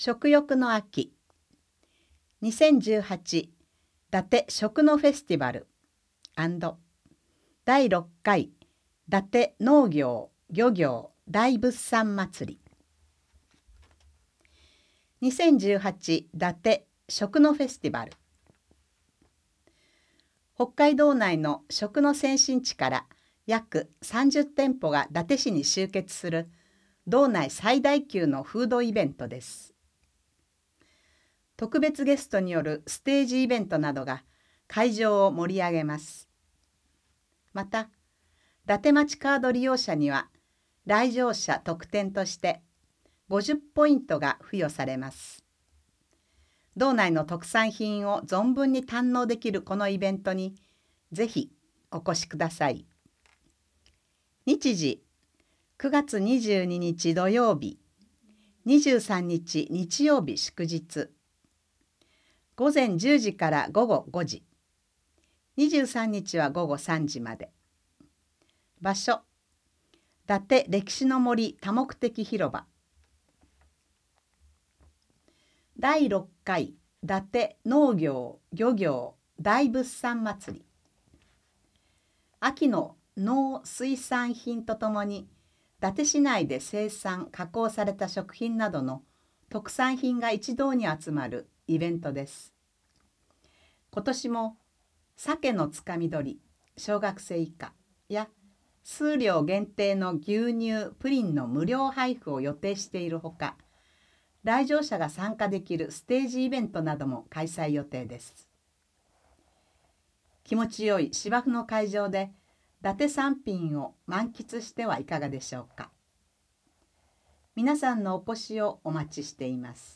内容をカセットテープに録音し、配布している事業です。
■朗読ボランティア「やまびこ」が音訳しています